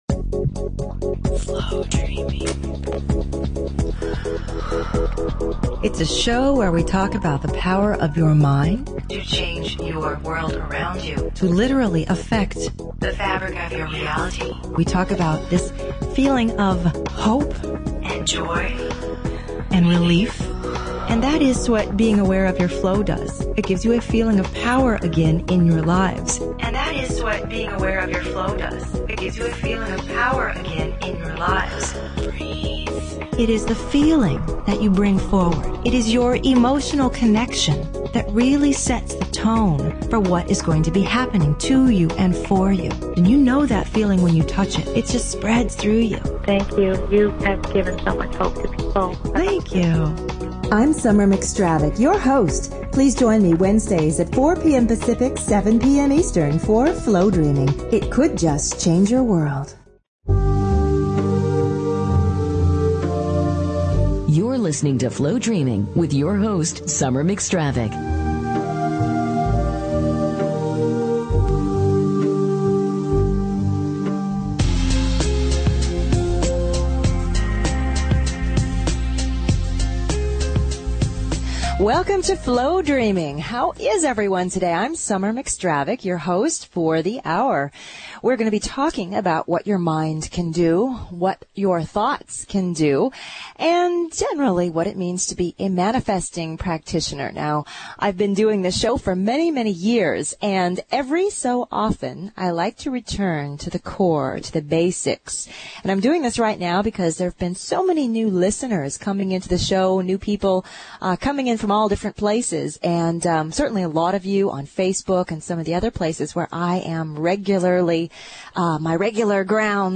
Talk Show Episode, Audio Podcast, Flowdreaming and Courtesy of BBS Radio on , show guests , about , categorized as